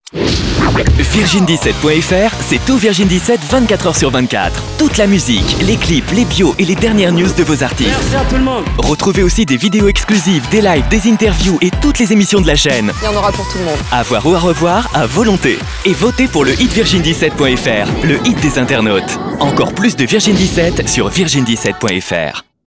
VIRGIN 17 promo - Comédien voix off
Genre : voix off.